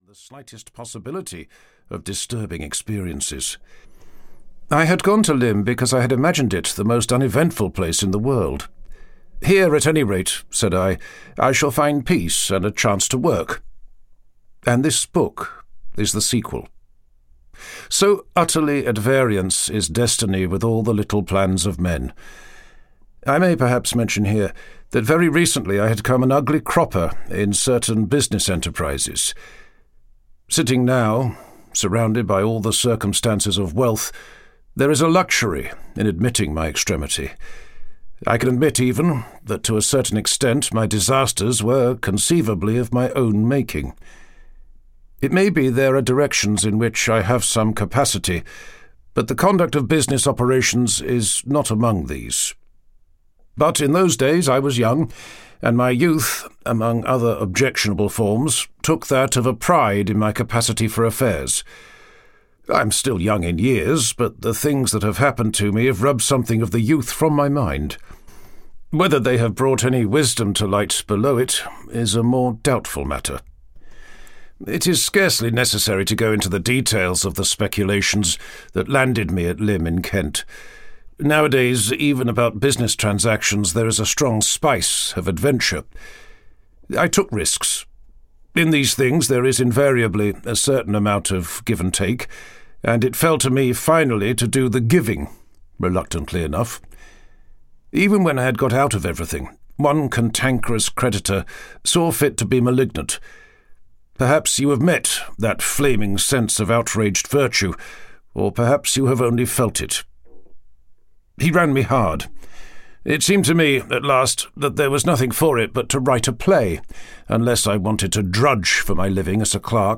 The First Men in the Moon (EN) audiokniha
Ukázka z knihy